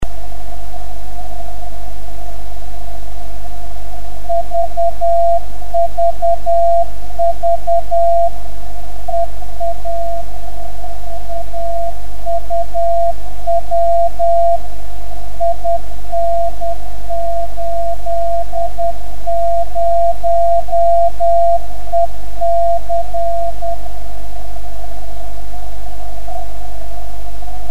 Die hier aufgeführten Stationen wurden von mir selbst empfangen.